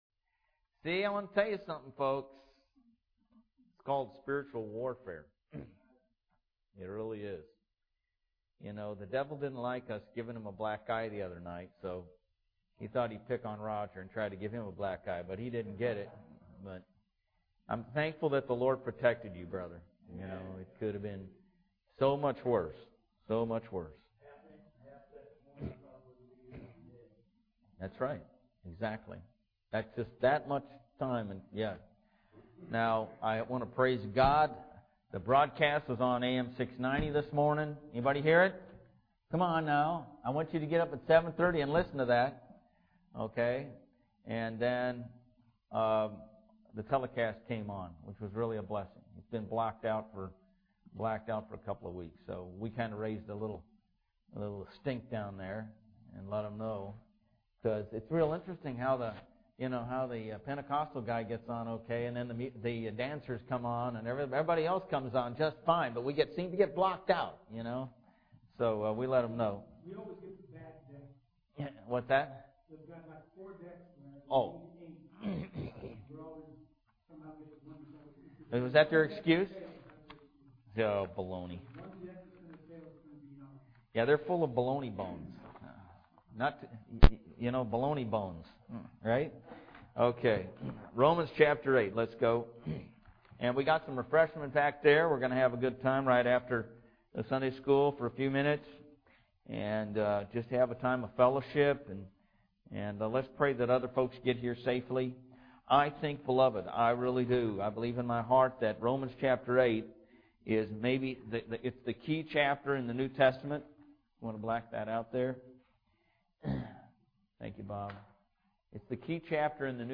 LISTEN TO SUNDAY SERVICES: